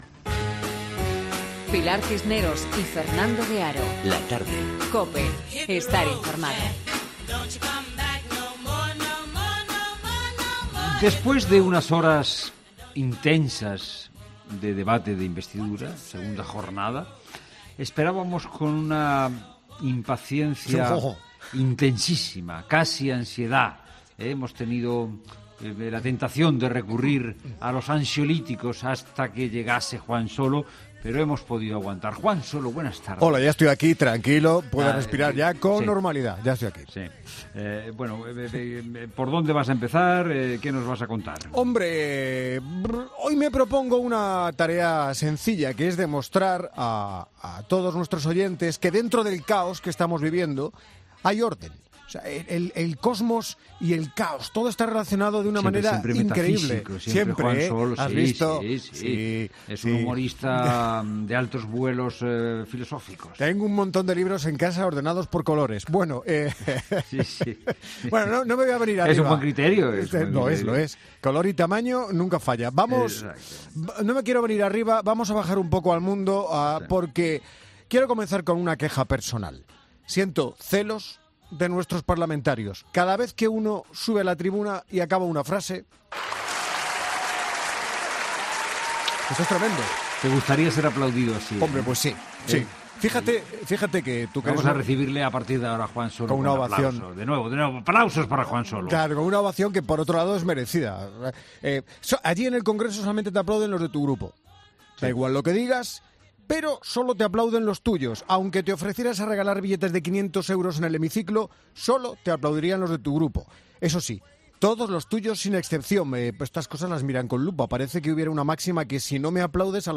'La Tarde', un programa presentado por Pilar Cisneros y Fernando de Haro, es un magazine de tarde que se emite en COPE, de lunes a viernes, de 15 a 19 horas.